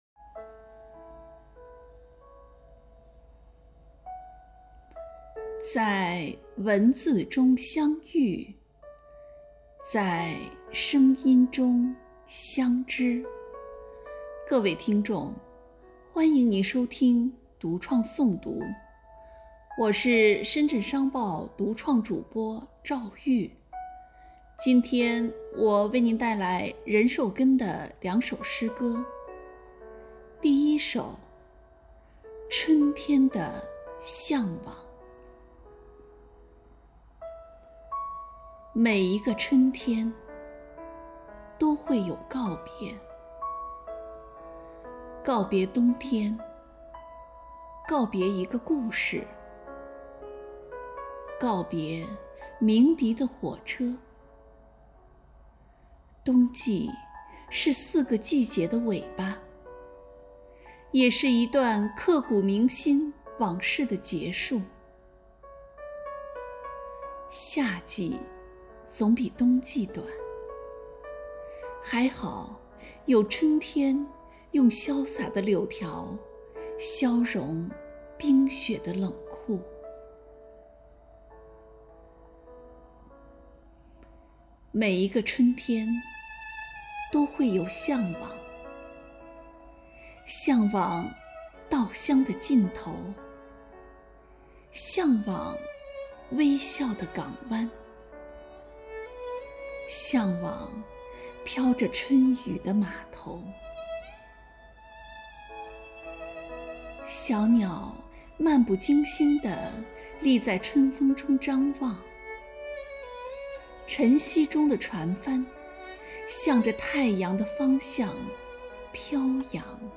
关于春天的诗歌朗诵,感受春天的美好与生机，一起用声音描绘春天的画卷